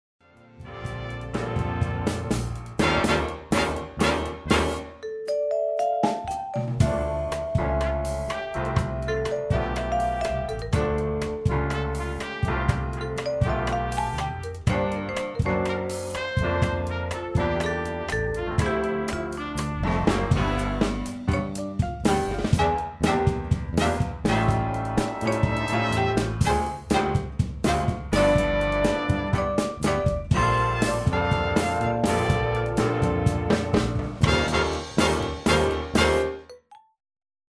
Vibes/Marimba Feature
is a sunshine-filled medium tempo Cha-Cha that grooves hard